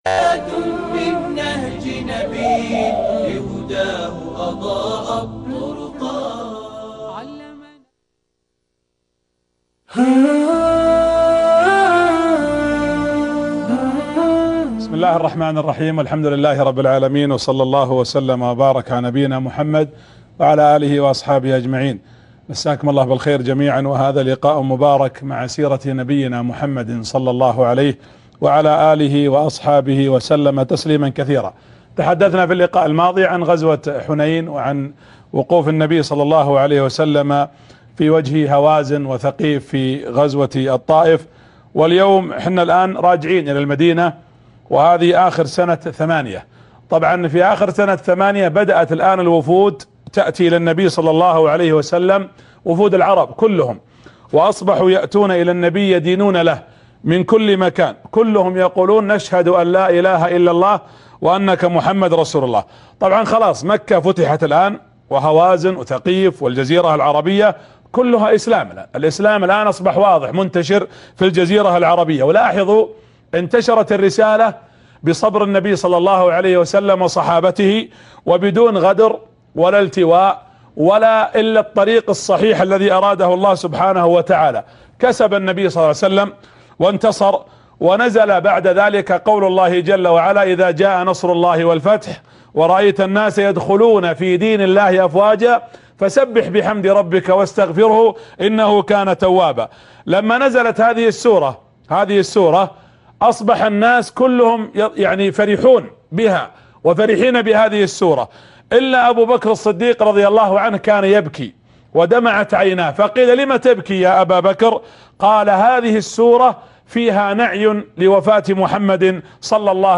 الحلقة الحادي عشر درس السيرة النبوية